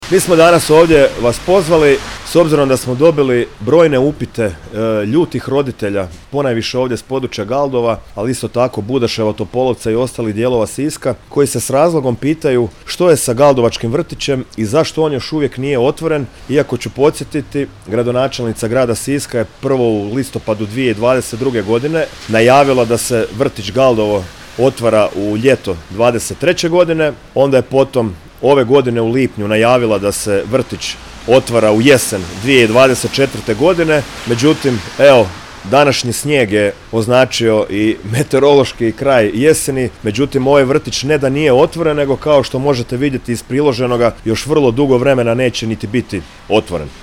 Gradska organizacija HDZ-a Sisak održala je u petak, 22. studenog 2024. godine, tiskovnu konferenciju ispred odgojno-obrazovnog kompleksa u Galdovačkoj ulici, na temu: „Poziv na otvorenje vrtića u Galdovu”.